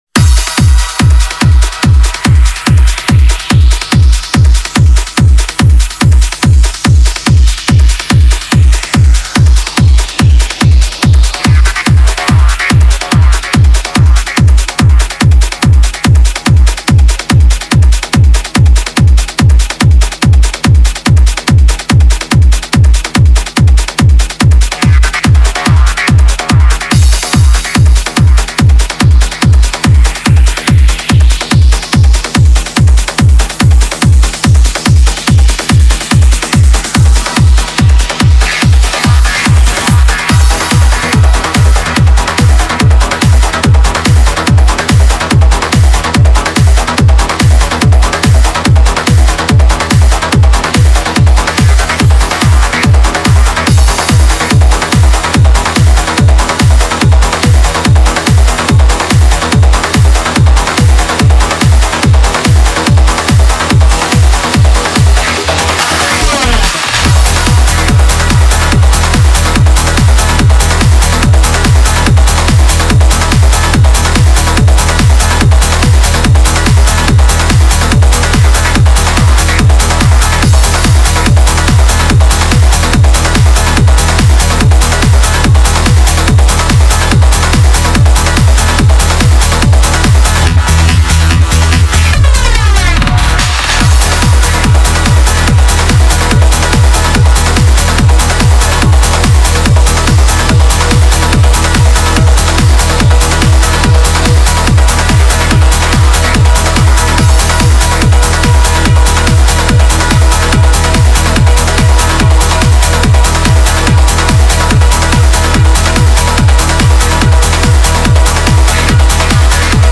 Home > Music > Ambient > Electronic > Restless > Fast